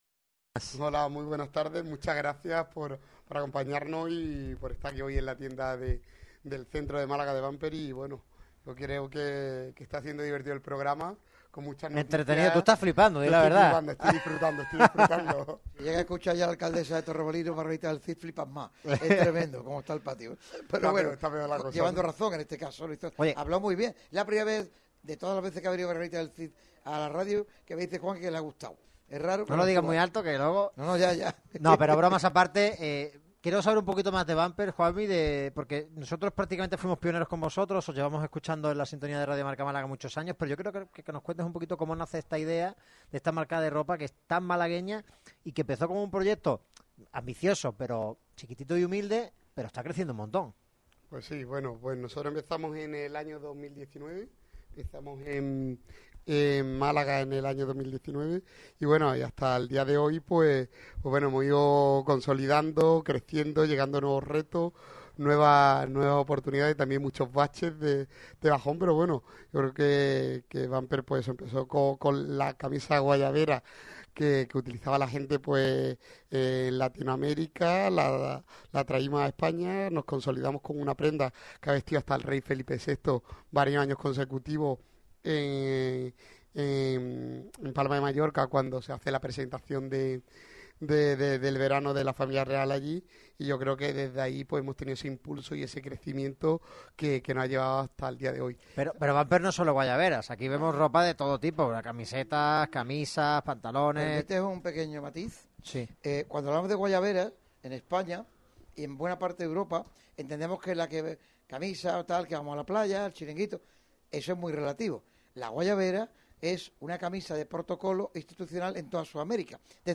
Este viernes 11 de julio se realizó el programa desde su tienda física de Málaga situada en Calle Sancha de Lara (Distrito Centro)